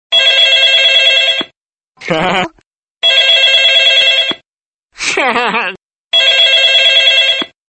Categoría Graciosos